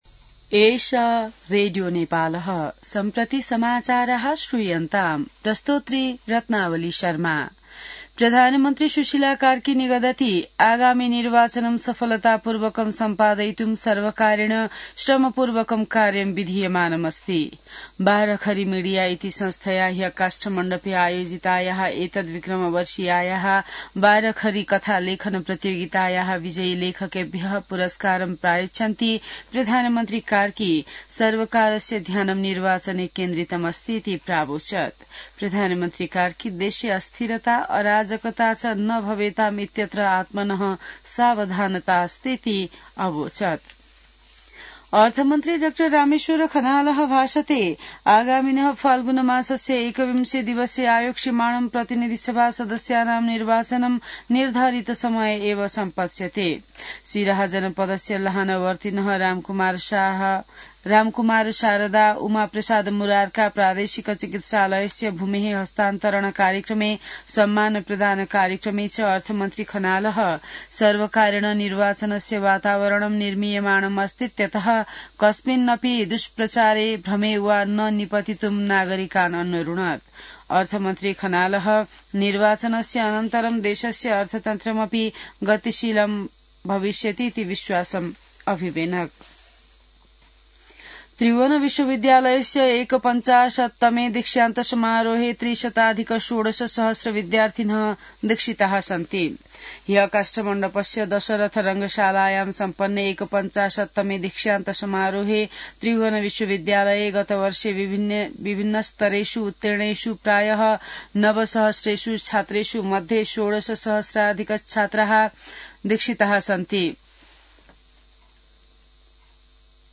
संस्कृत समाचार : ११ पुष , २०८२